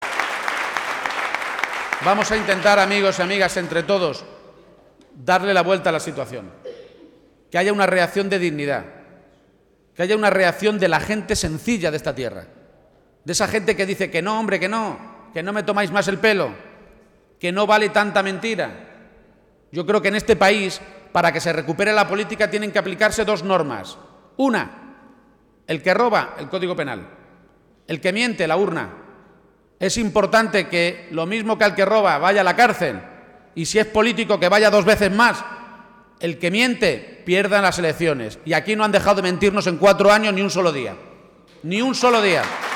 García-Page avanzaba esta medida esta tarde, en un acto público celebrado ante más de 300 personas en Villacañas, en La Mancha toledana, en el que volvía a insistir en que, además, los altos cargos del nuevo Gobierno regional estarán obligados a pagar sus impuestos en Castilla-La Mancha.